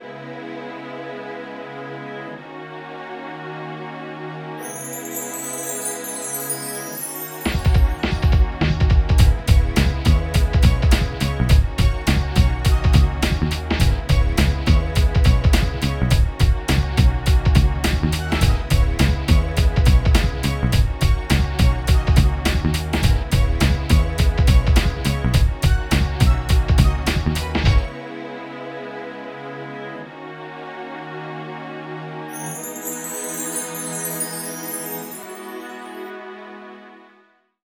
14 LOOP   -R.wav